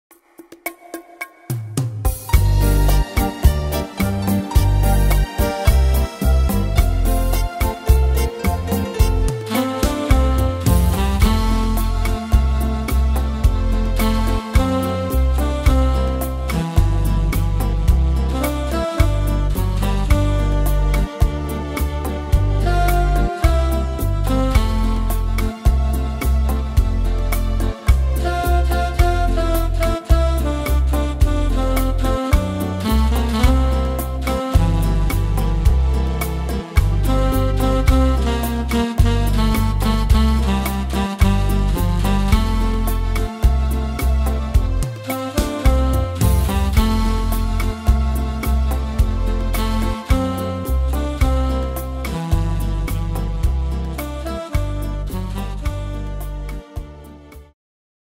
Tempo: 180 / Tonart: F-Dur